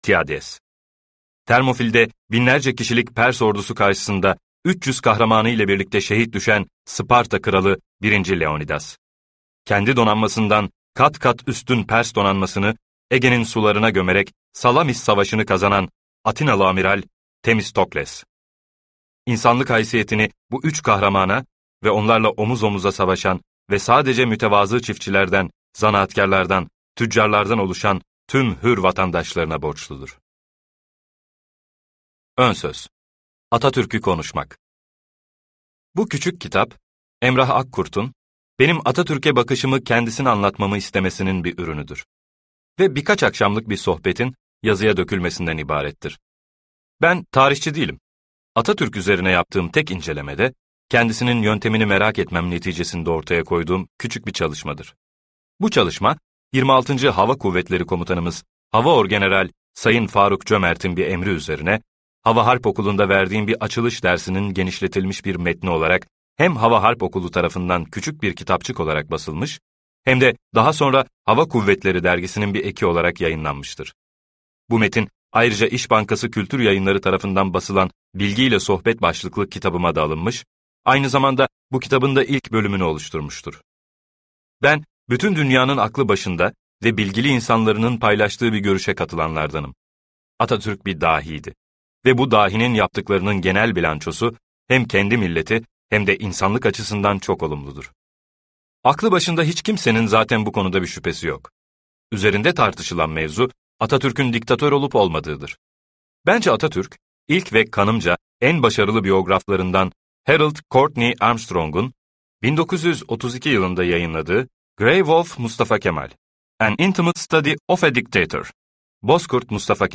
Dahi Diktatör - Seslenen Kitap